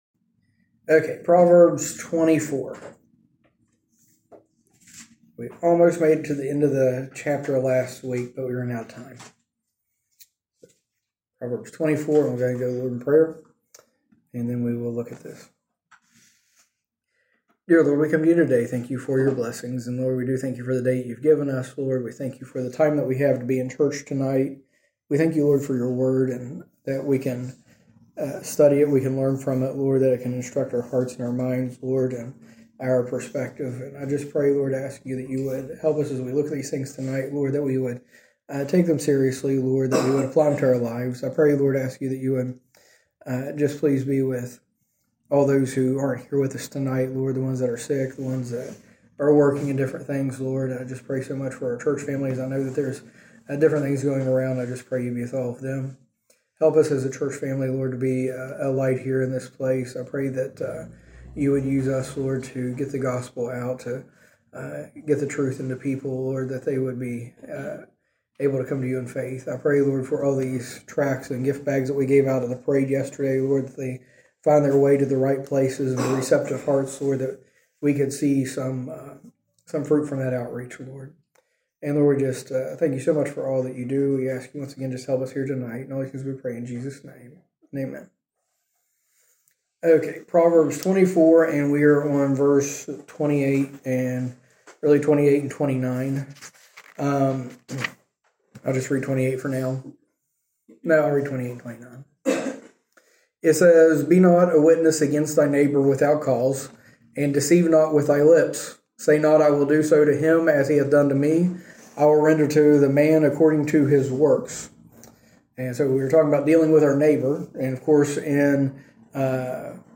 From Series: "Recent Sermons"